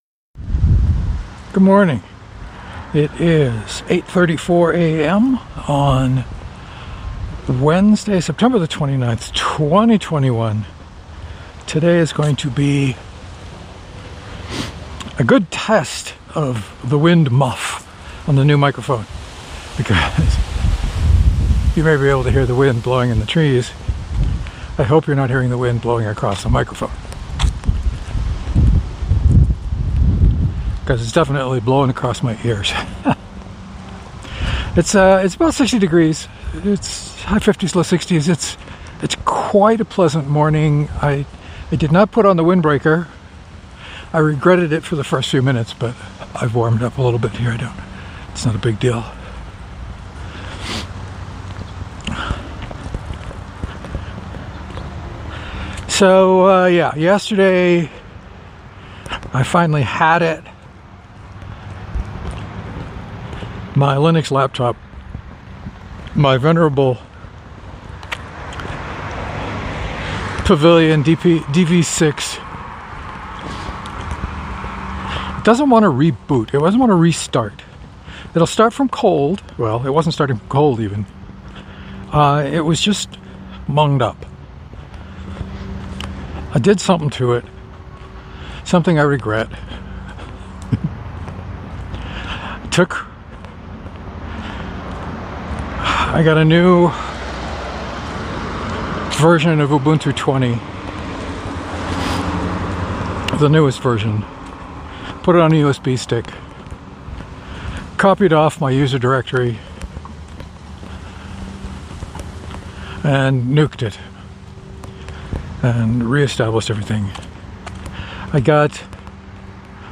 The new windsock seems fine. I heard some distant wind-through-the-trees but no wind blowing past the mic.